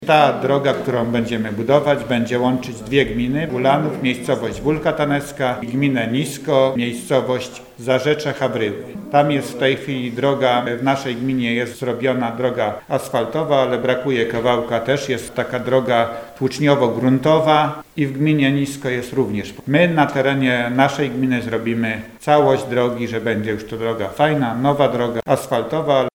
Jeszcze w tym roku mieszkańcy Wólki Tanewskiej w gminie Ulanów doczekają się przebudowy ulicy Cegielnianej. Burmistrz Ulanowa Stanisław Garbacz przyznał, ze jest to ważna droga nie tylko dla mieszkańców Wólki: